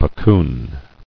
[puc·coon]